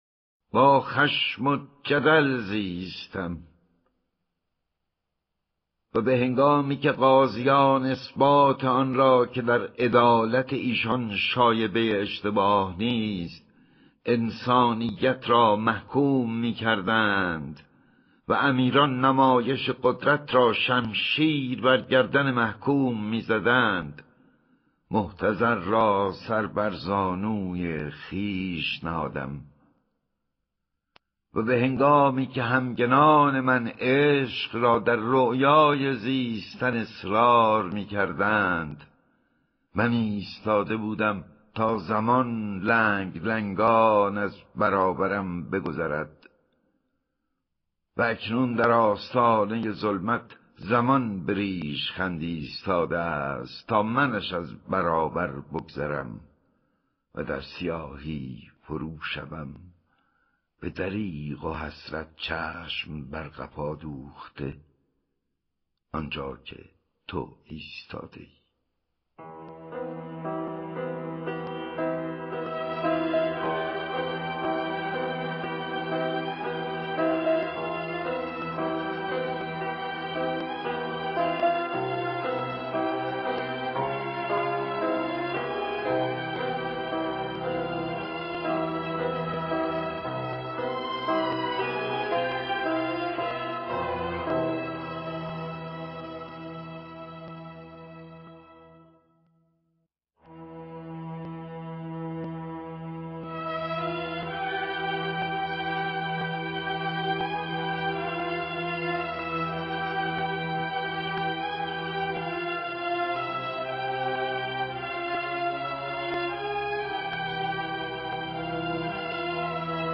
دانلود دکلمه با خشم و جدل زیستن با صدای احمد شاملو
گوینده :   [احمد شاملو]